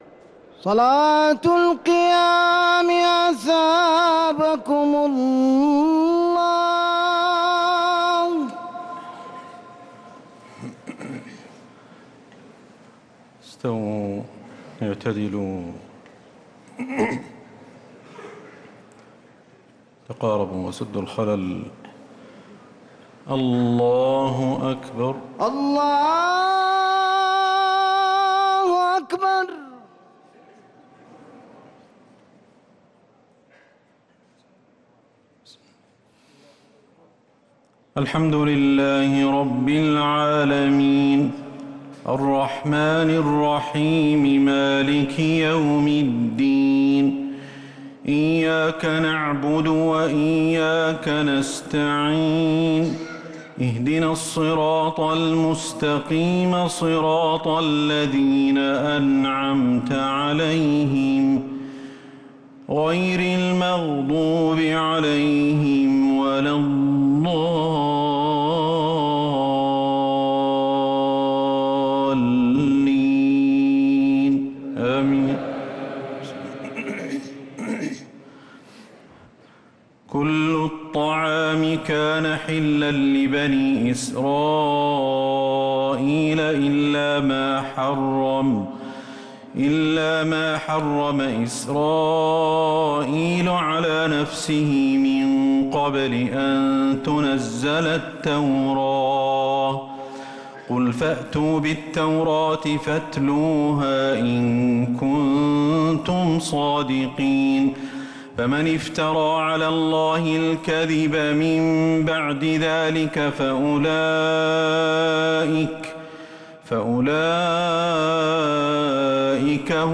تراويح ليلة 5 رمضان 1446هـ من سورة آل عمران (93-158) | Taraweeh 5th night Ramadan 1446H Surat Aal-i-Imraan > تراويح الحرم النبوي عام 1446 🕌 > التراويح - تلاوات الحرمين